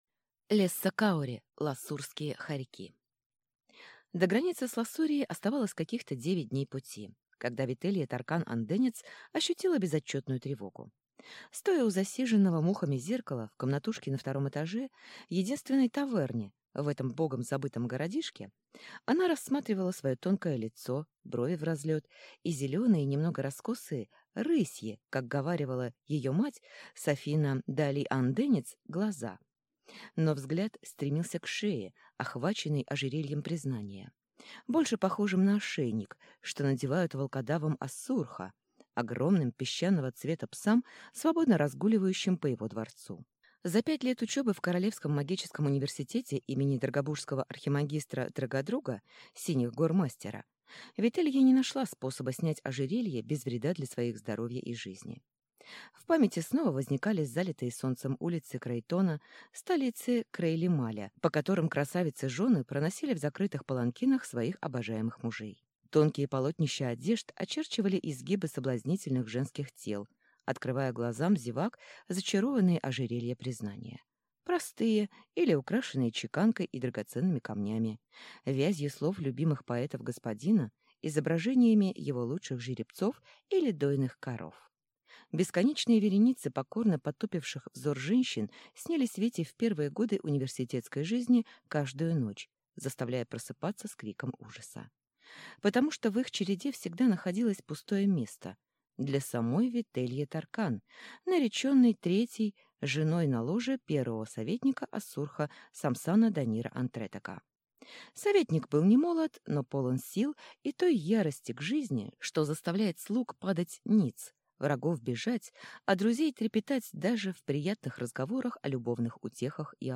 Аудиокнига Ласурские хорьки | Библиотека аудиокниг